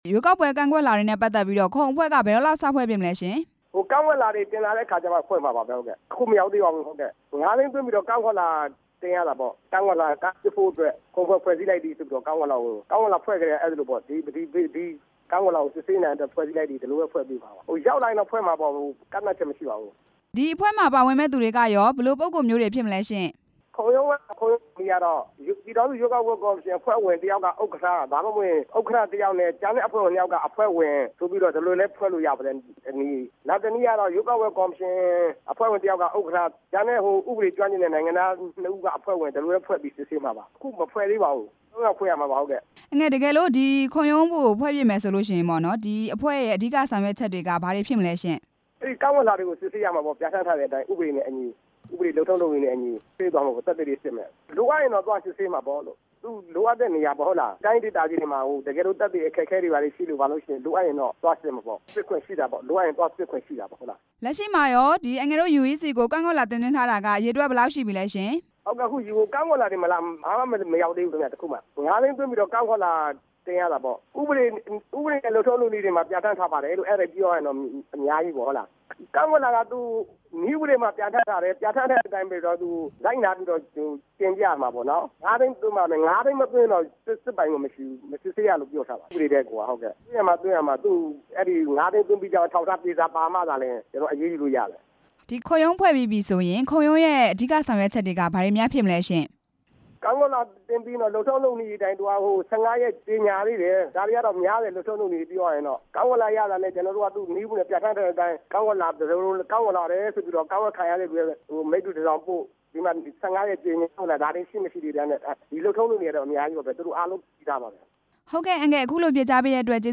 ပြည်ထောင်စုရွေးကောက်ပွဲ ကော်မရှင်အဖွဲ့ဝင် ဦးမြင့်နိုင်နဲ့ မေးမြန်း ချက်